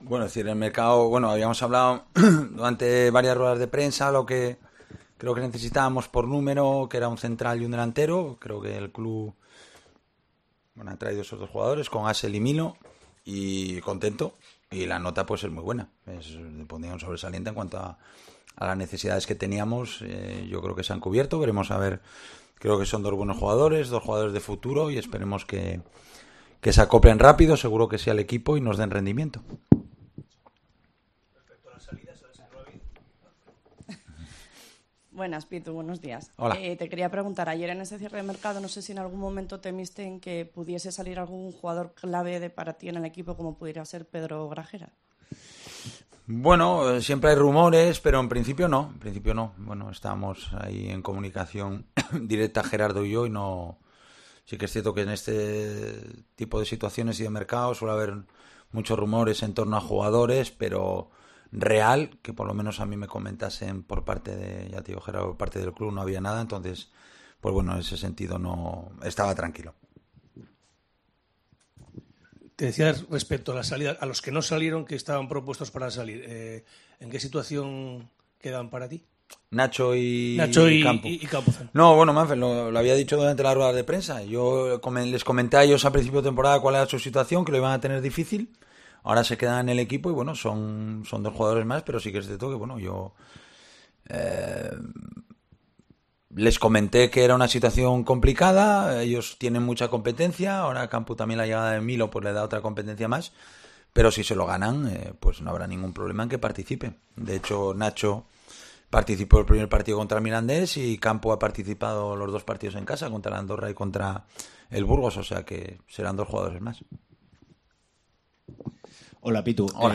Rueda de prensa Abelardo (previa Ponferradina)